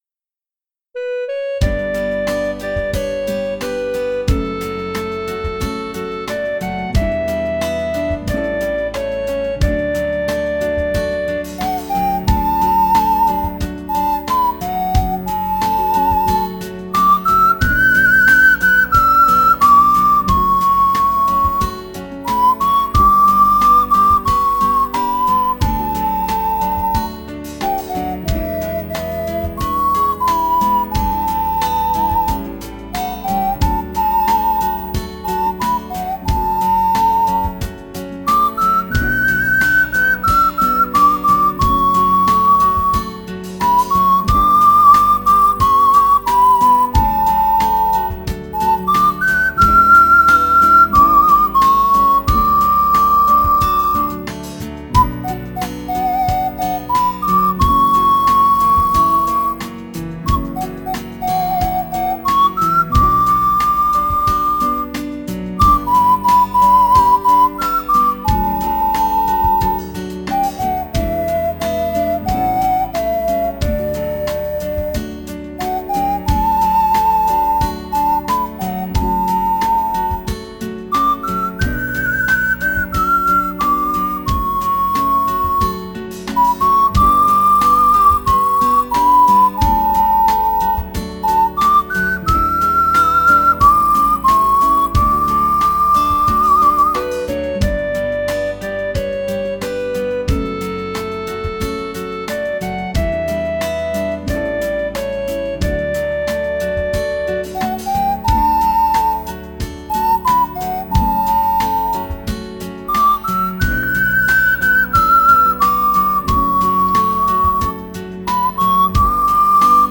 鼻笛練習用音楽素材
鼻笛教室等で使用の練習用素材はこちらにまとめます。
瀬戸の花嫁　サンプル演奏付